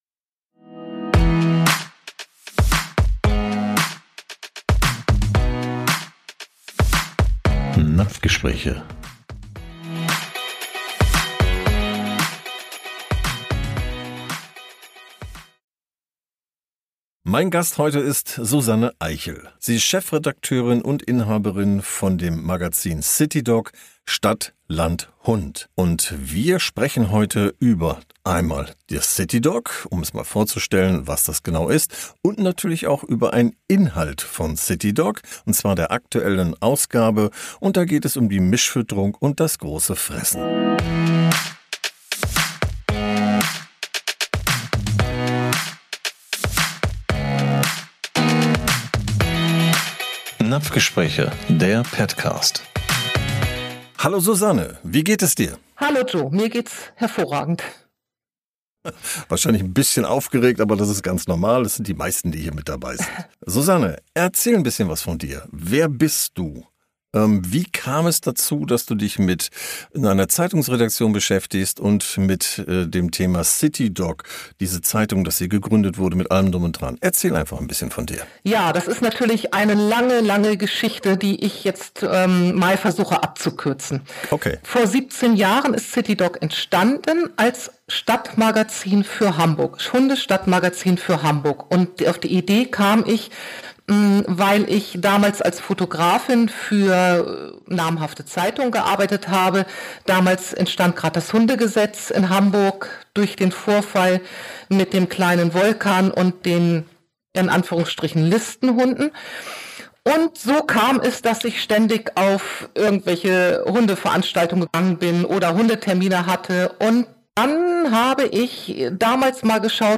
Unser heutiger Interviewgast